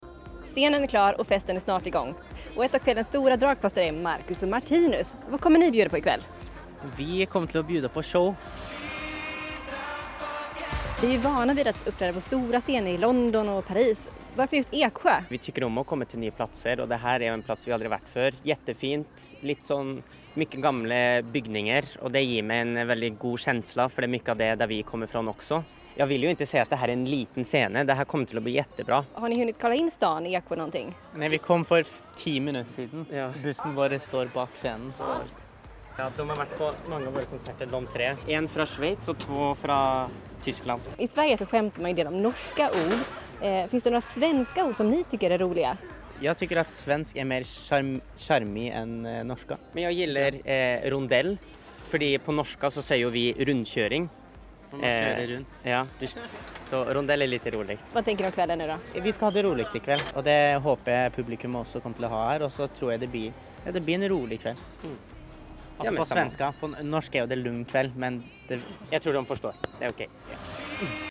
Marcus and Martinus got interviewed in Eskjö. They said they will give an awesome show and they love traveling to new cities.